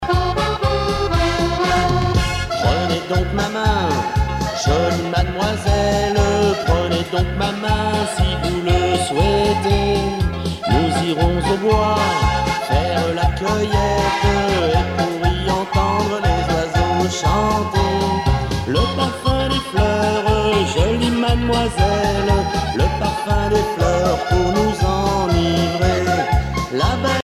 danse : laridé, ridée
Pièce musicale éditée